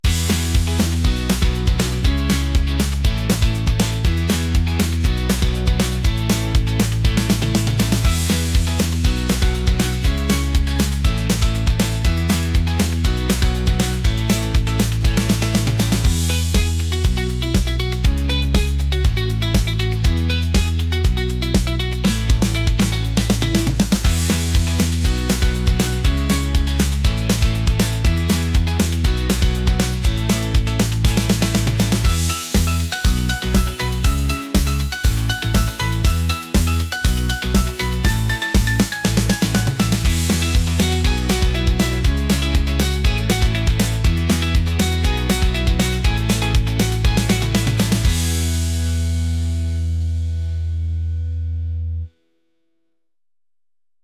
upbeat | catchy | pop